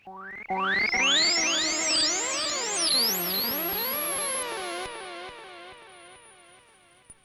Bee Sweep.wav